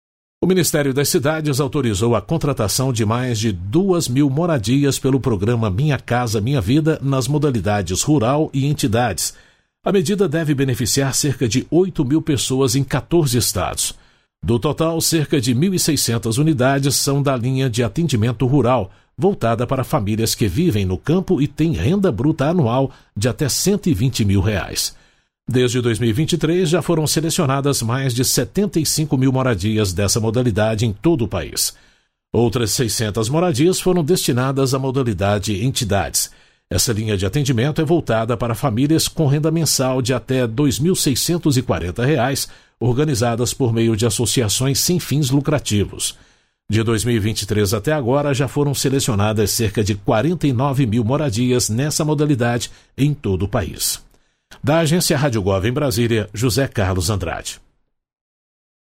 É Notícia